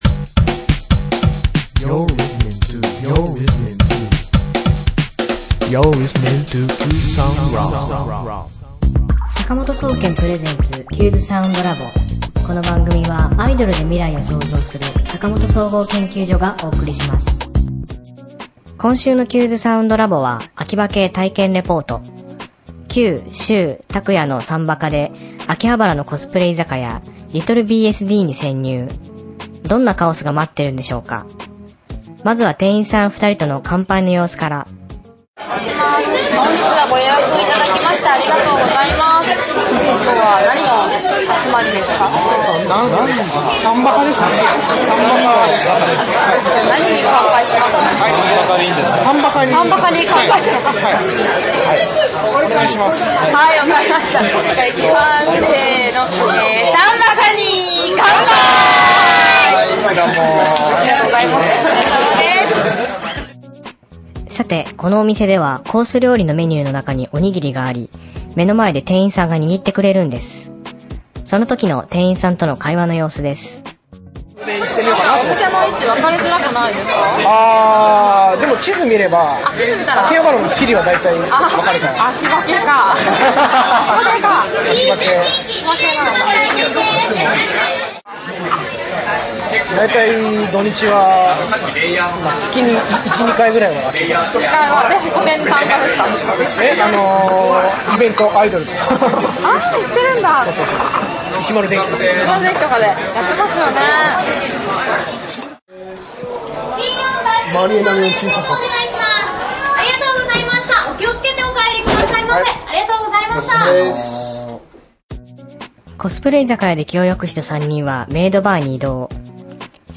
今週のテーマ：アキバ系体験記（コスプレ居酒屋・メイドバー）
カオスをお楽しみください。
今週の挿入歌『新曲（untitled A）』